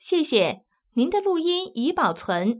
ivr-recording_saved.wav